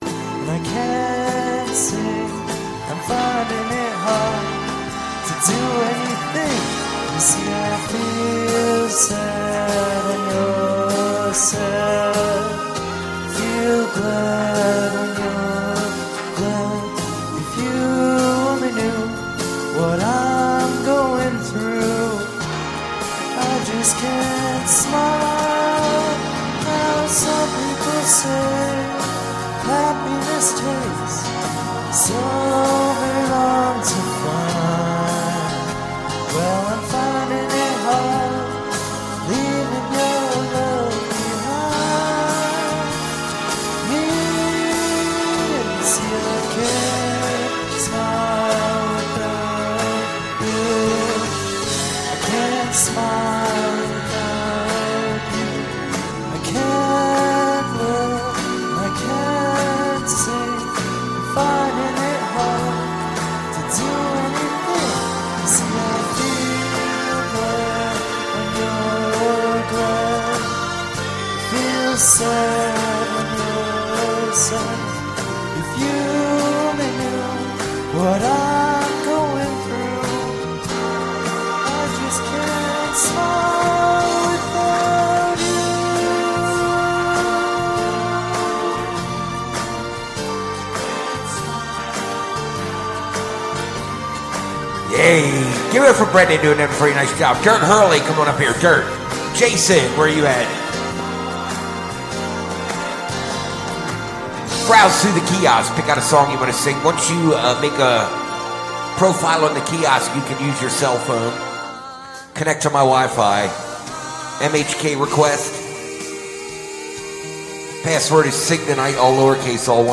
We are live 8-12 every Sunday and Wednesday from the Morrison Holiday Bar